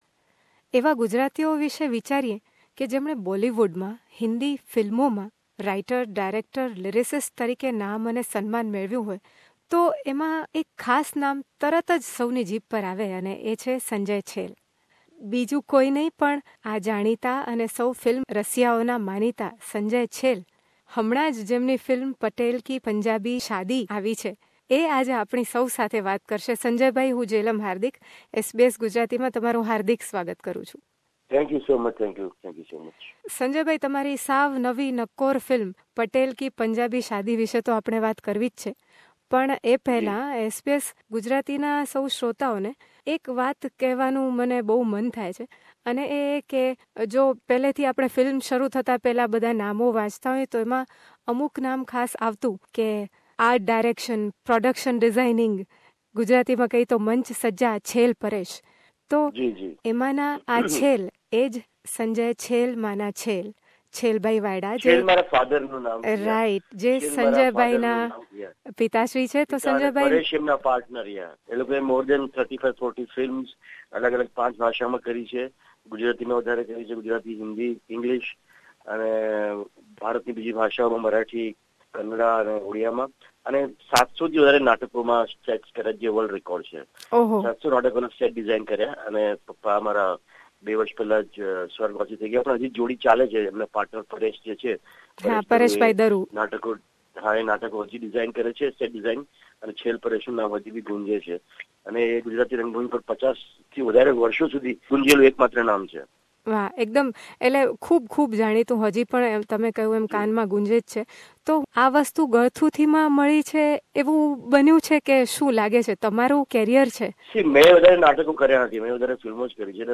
Dialogue writer of movies like 'Rangeela' and 'Indu Sarkar', Sanjay Chhel has art and creativity in his blood. In this conversation with SBS Gujarati, he shares his journey of writing for newspapers and films until his recently released movie 'Patel ki Punjabi Shaadi'.